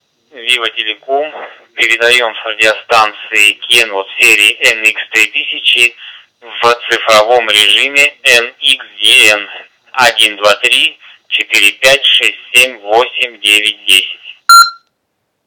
Пример модуляции (передачи) радиостанций серии NX-3000 в цифровом режиме NXDN:
nx-3000-tx-nxdn.wav